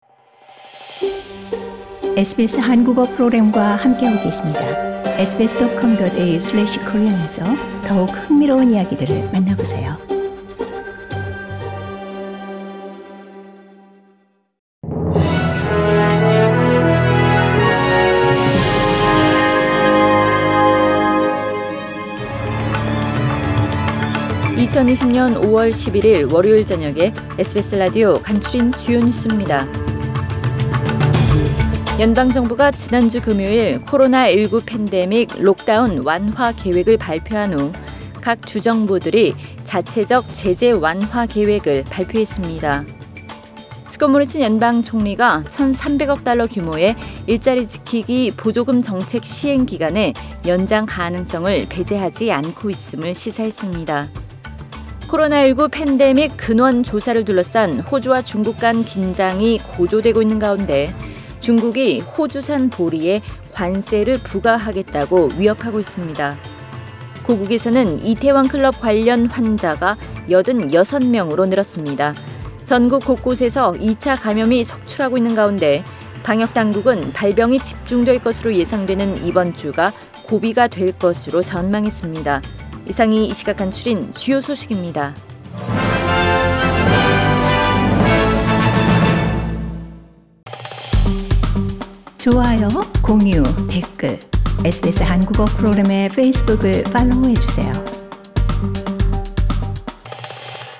SBS 한국어 뉴스 간추린 주요 소식 – 5월 11일 월요일